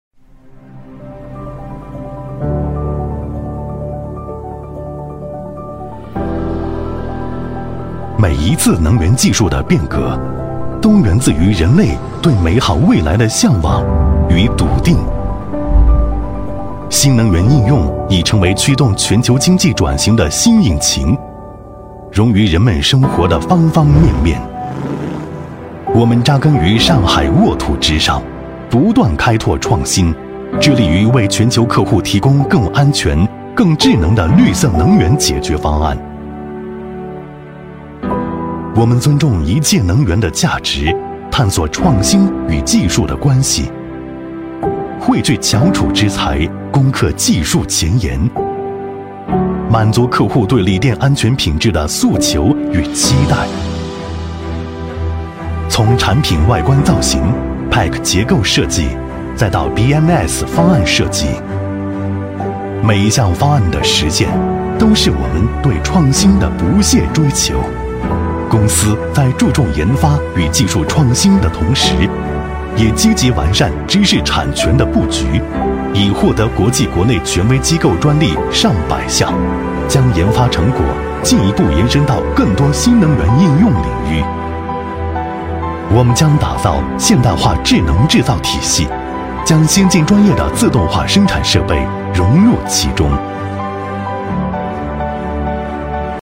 红樱桃配音，真咖配音官网—专业真人配音服务商！